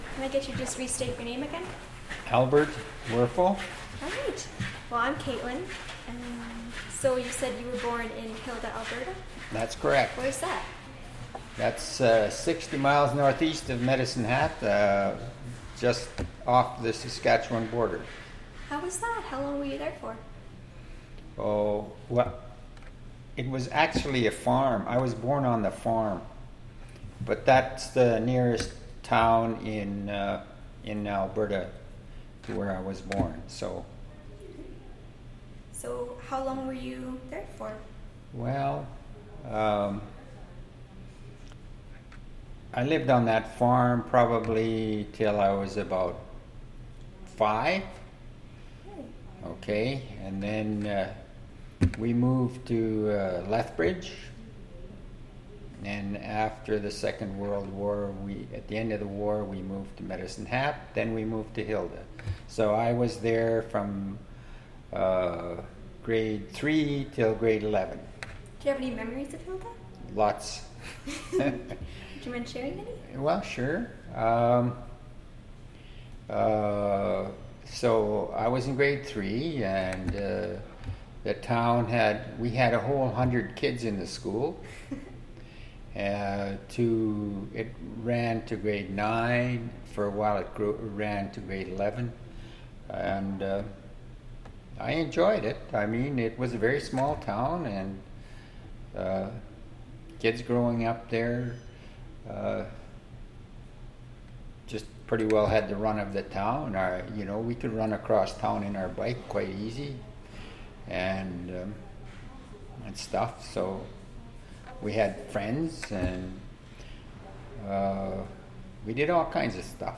Audio interview and transcript of audio interview,